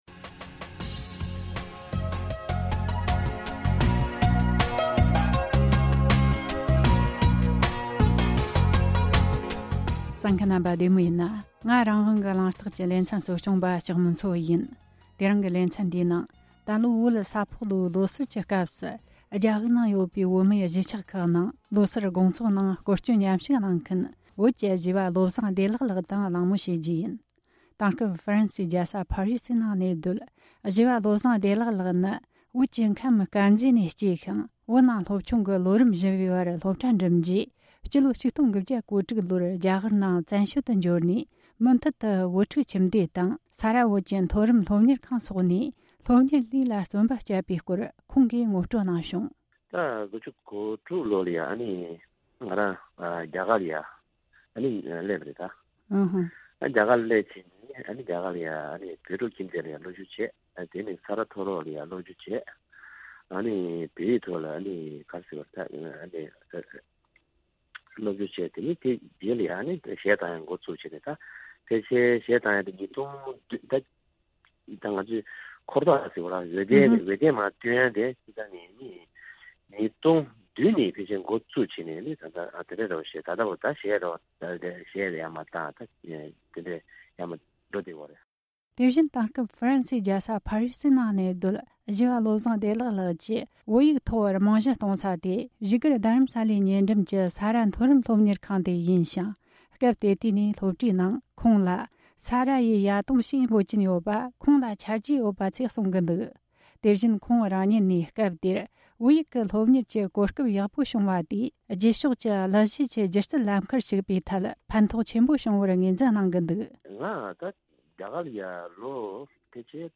ལྷན་གླེང་བ།